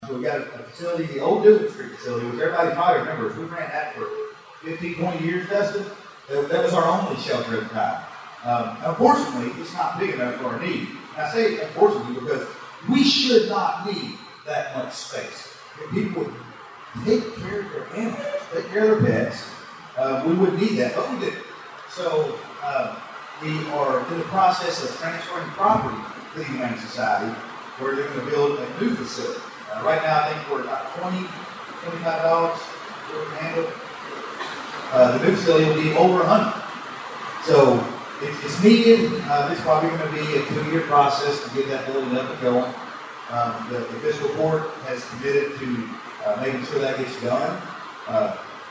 The Hopkins County Humane Society’s shelter and the economic impact of the Kentucky Sports Factory were among topics discussed last week at the State of the Cities and County event, hosted by the Hopkins County Regional Chamber of Commerce.